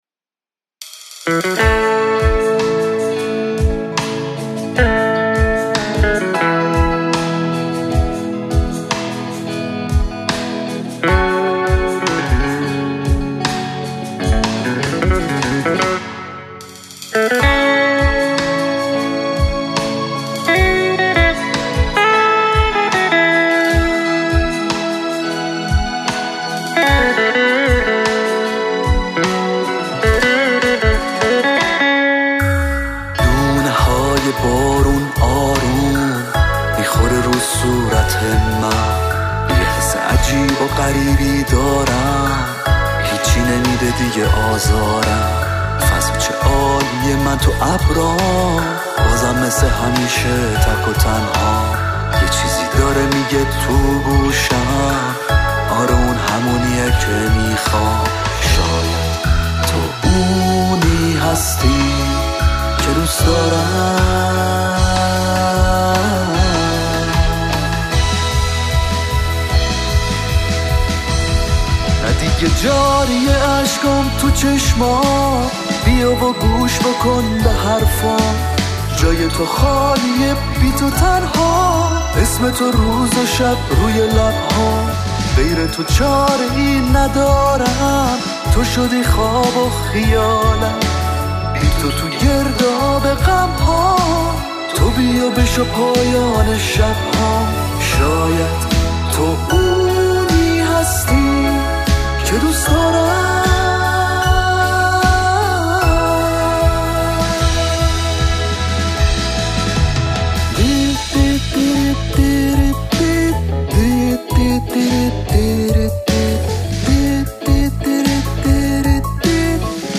سبک: پاپ – راک